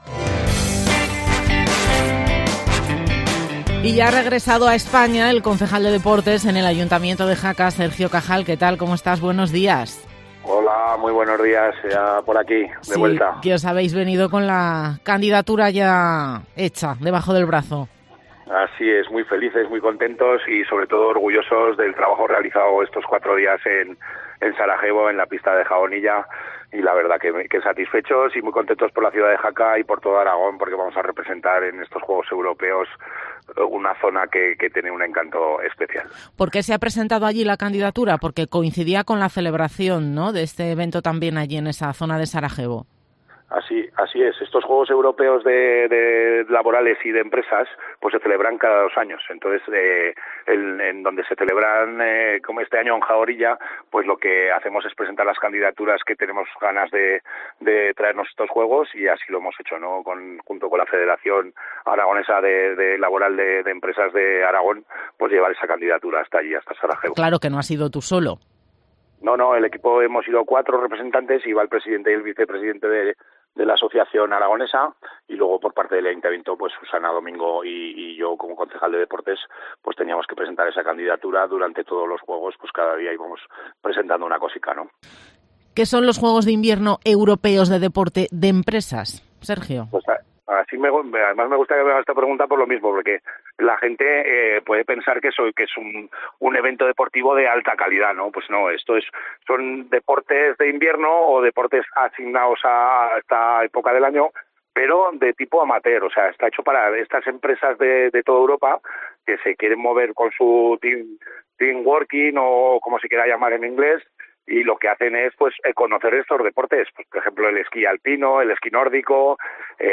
Sergio Cajal, concejal de Deportes en el Ayuntamiento de Jaca